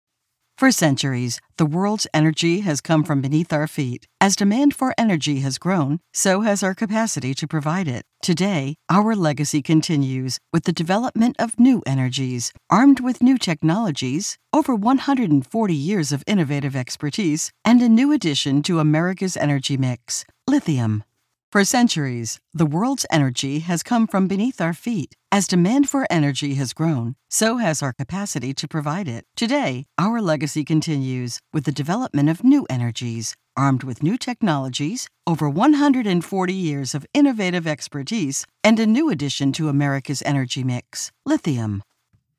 Female
My voice is very versatile, warm, conversational, and real.
Explainer Videos
Words that describe my voice are Warm, Conversational, Sophisticated.